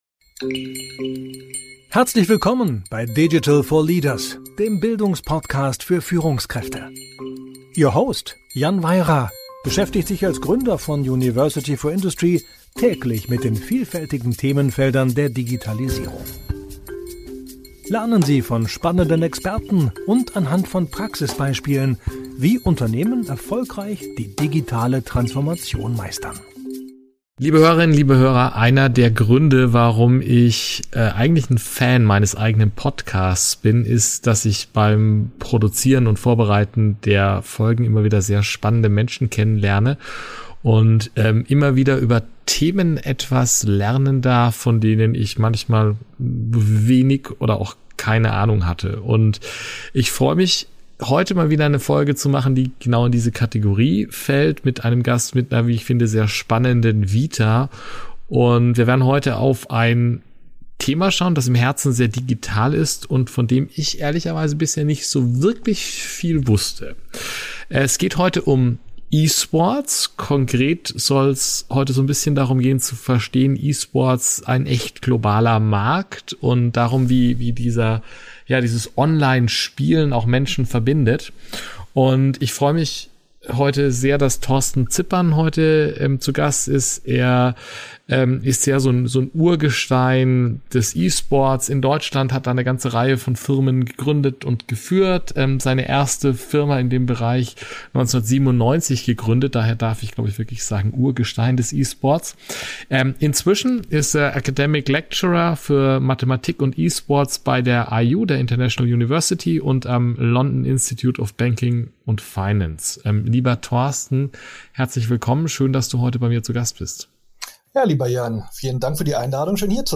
Die beiden diskutieren die beeindruckenden Zuschauerzahlen und die Reichweite des E-Sports sowie die verschiedenen Geschäftsmodelle und Einnahmequellen, die diese Branche prägen. Ein weiterer Schwerpunkt liegt auf der Rolle der Teams und Spieler im E-Sports, der Bedeutung der Virtual Bundesliga und der globalen Perspektive des E-Sports in verschiedenen Ländern. Besonders interessant sind die Ausführungen zu Saudi-Arabiens Investitionen und deren Einfluss auf den E-Sports sowie die strategischen Wetten und Zukunftsaussichten dieser dynamischen Branche.